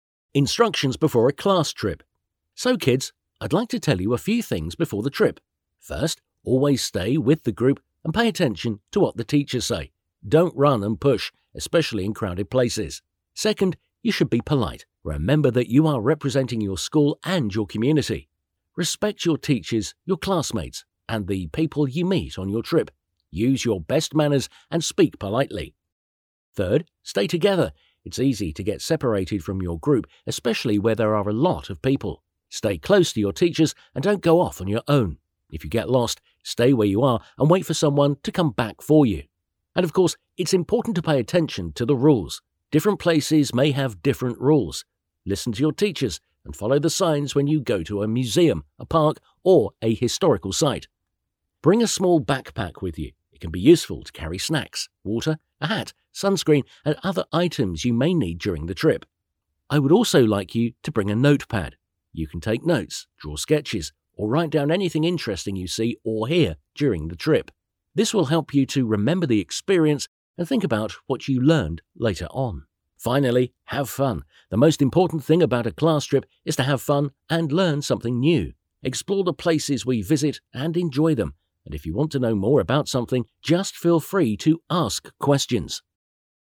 Listen to the teacher giving instructions to the kids before the class trip and fill in the gaps in the summary of it below.